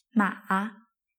第3声の発音は、低い音から始まって低いところで終わるのが特徴です。
しかし、最後は上げるというよりも、声を低く抑えて最後に力を抜くとき少し上がるように聞こえると思ってください。
日本語で言うとがっかりしたときの「あ～あ」のイメージが近いです。